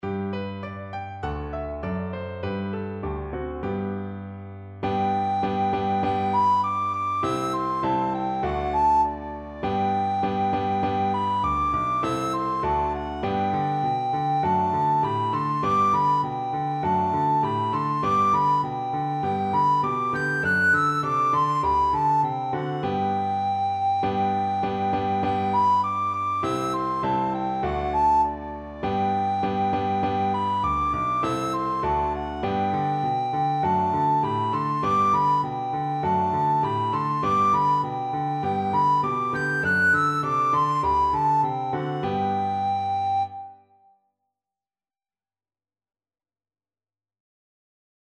Soprano (Descant) Recorder version
Two in a bar =c.100
2/2 (View more 2/2 Music)
D6-G7